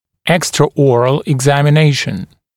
[ˌekstrə’ɔːrəl ɪgˌzæmɪ’neɪʃn] [eg-] [ˌэкстрэ’о:рэл игˌзэми’нэйшн] [эг-] внешнее обследование, внеротовое обследование